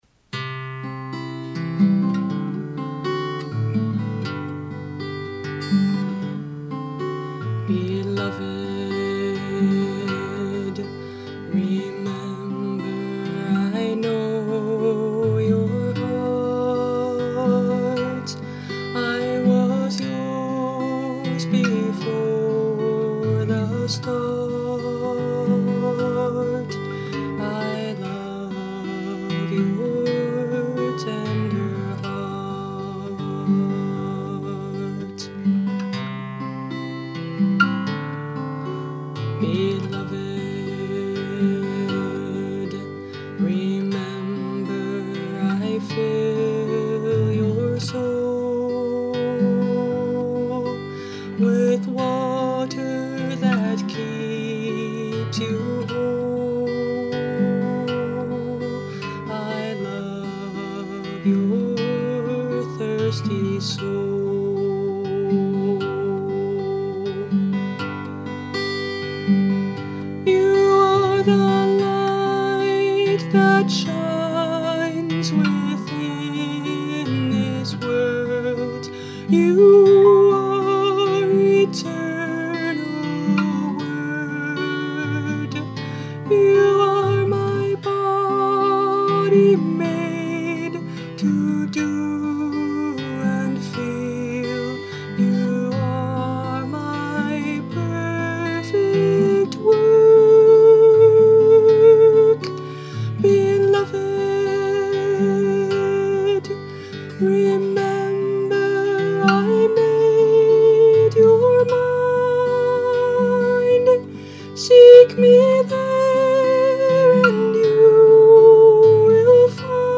A song for meditation and affirmation.
Instrument: Tempo – Seagull Excursion Folk Acoustic Guitar